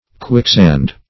Quicksand \Quick"sand`\, n.